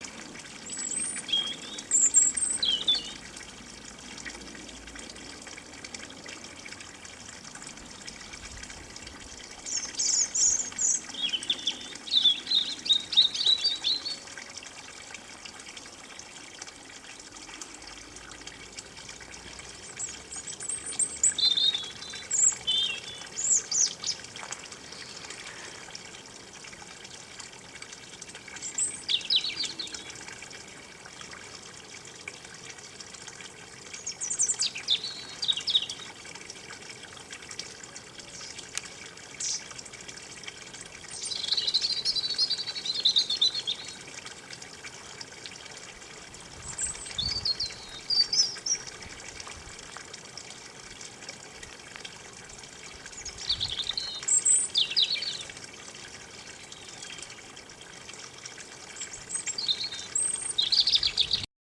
There is something very inspiring about this friendly little bird surviving the winter months against all odds, and yet whose song rings out in the ice-bound sky announcing the oncoming spring.
robin-bird-song.mp3